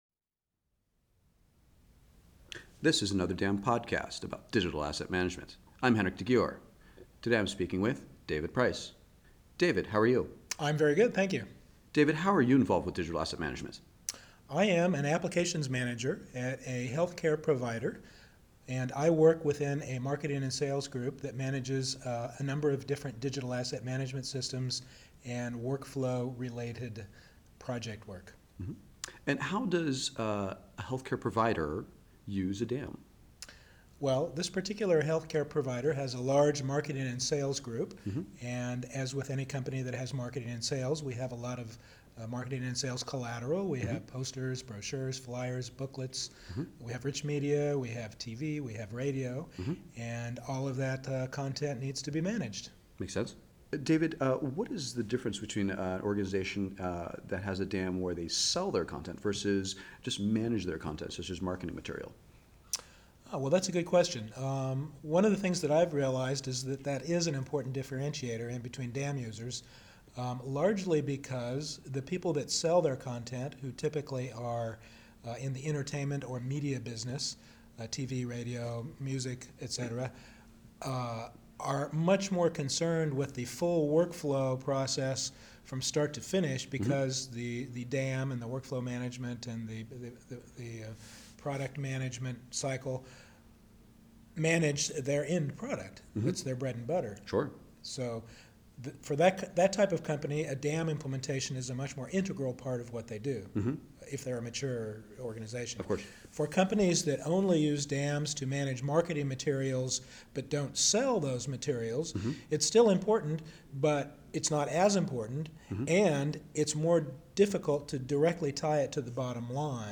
Every person interviewed is asked at least three similar questions: